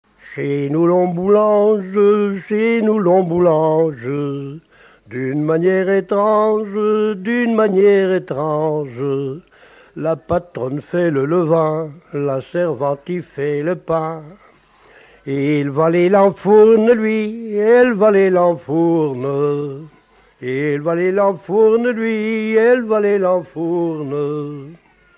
danse : ronde : grand'danse
Pièce musicale inédite